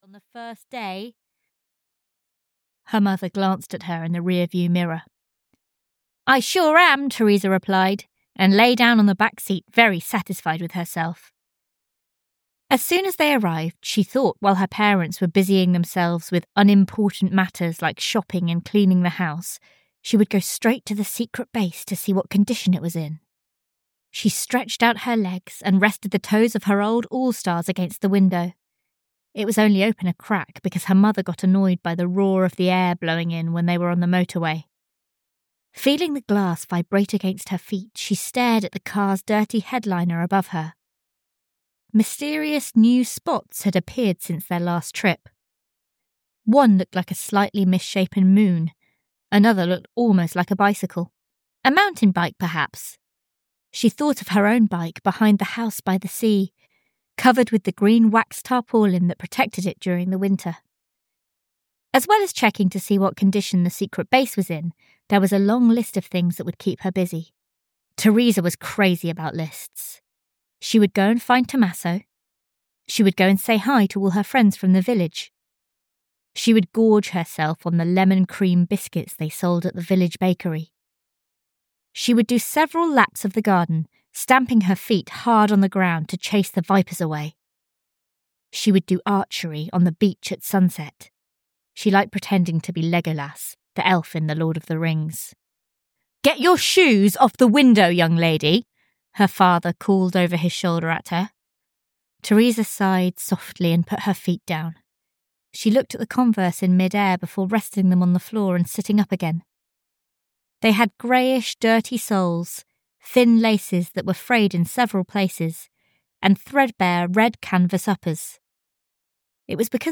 Like Cicadas (EN) audiokniha
Ukázka z knihy